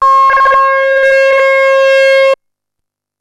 E T TALKS 5.wav